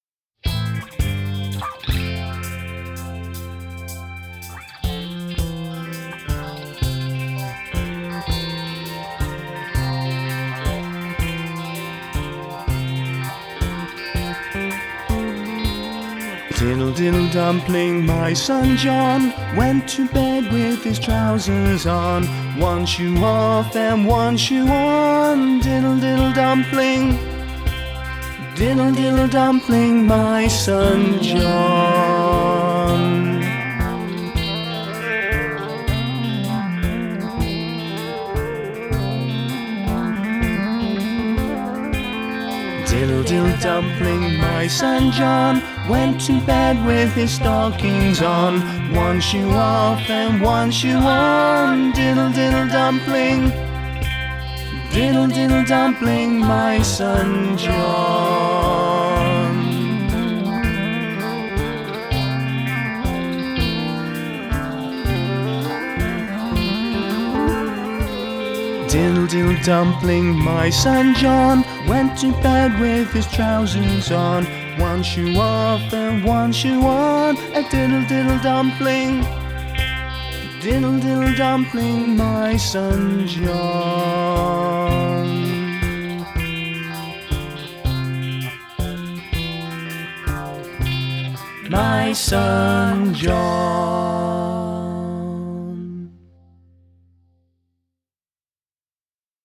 Traditional